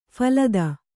♪ phalada